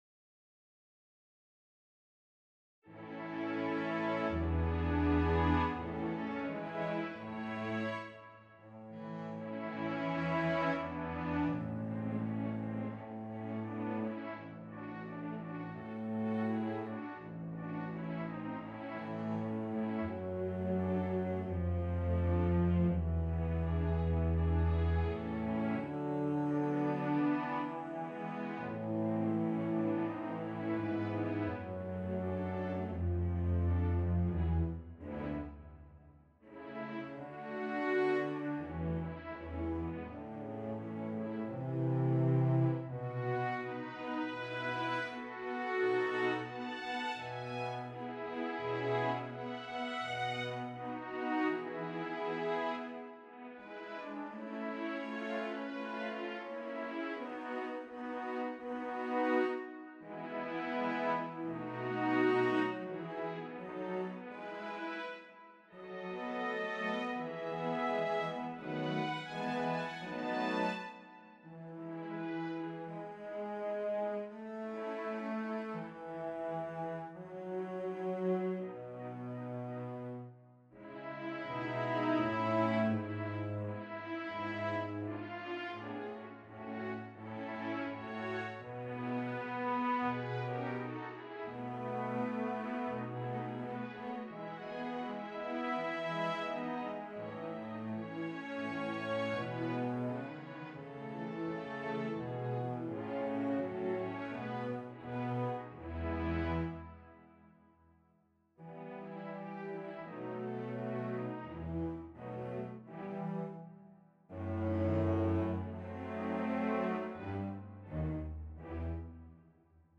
Play (or use space bar on your keyboard) Pause Music Playalong - Piano Accompaniment transpose reset tempo print settings full screen
Violin
2/4 (View more 2/4 Music)
A major (Sounding Pitch) (View more A major Music for Violin )
Andante =c.42
Classical (View more Classical Violin Music)